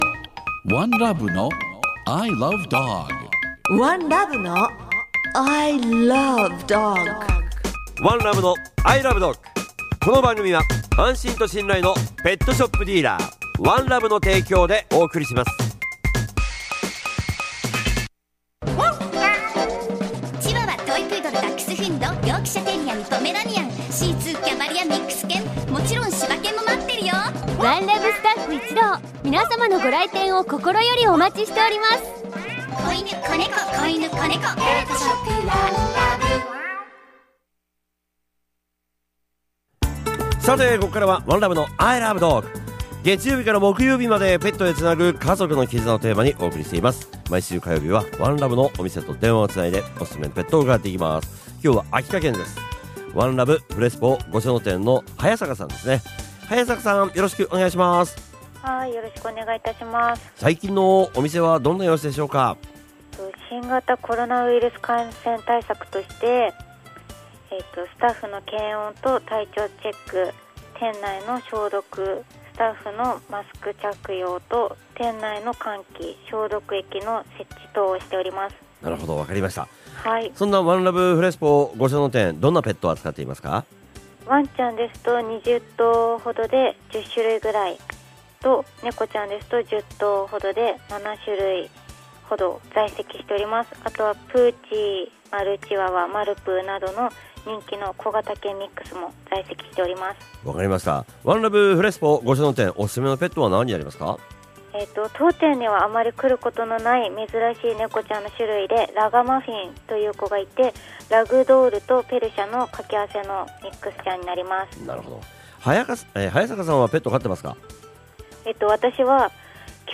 月曜は、街角突撃インタビューが聞けるワン！